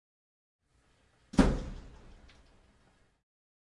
烘干机门关闭
描述：干衣机门关闭